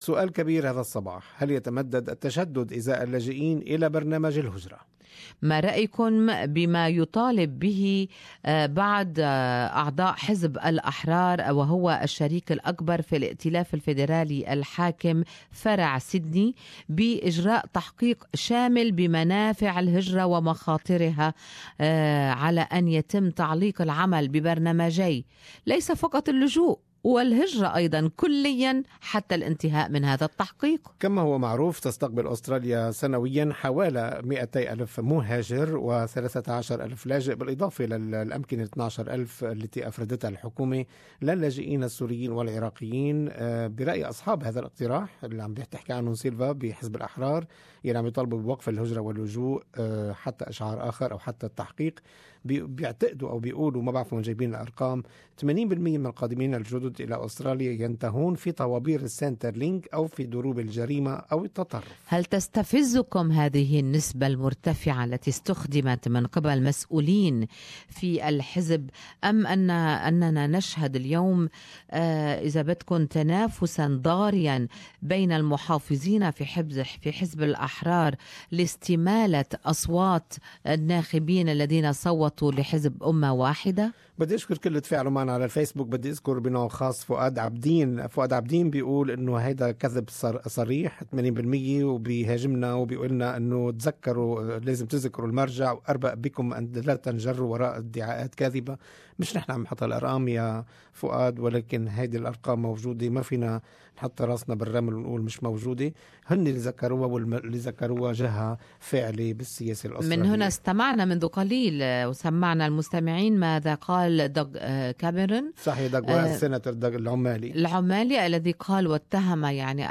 Talkback: Asylum Seekers Ban and what after